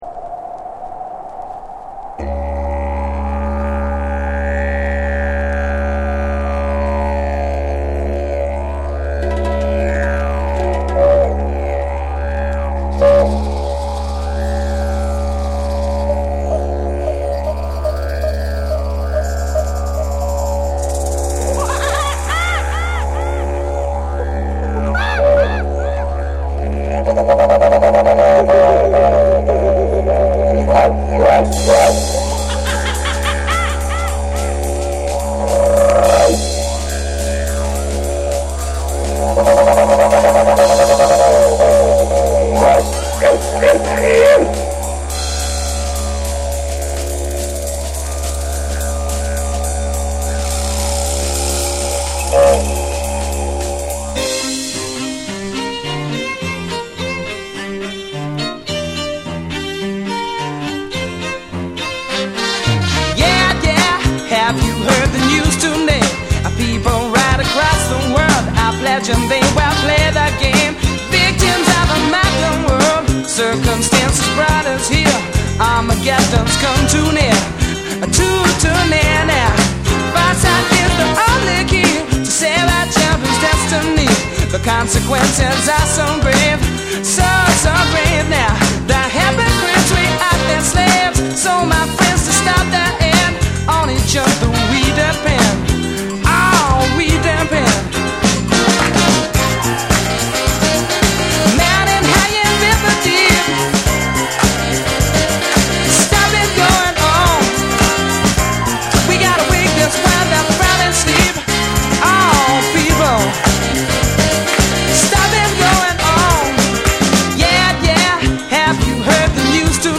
ディジュリドゥーのイントロがインパクト大な